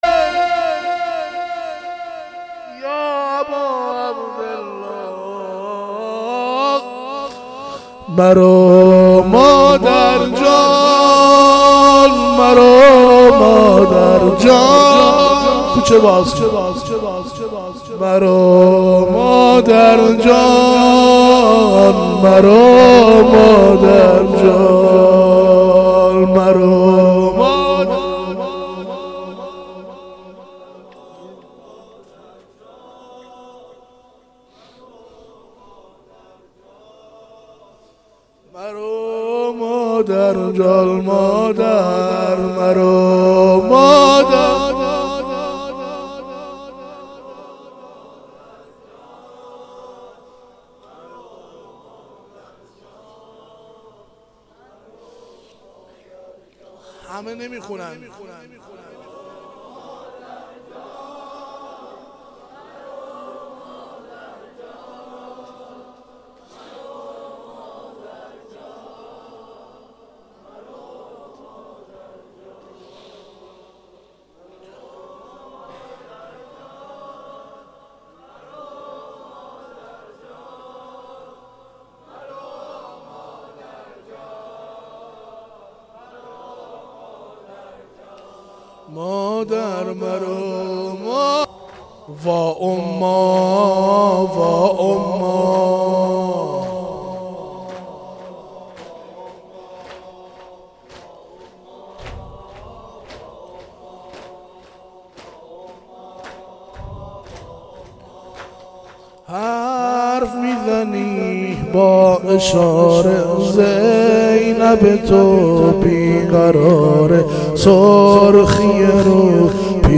زمینه-شب دوم فاطمیه دوم 95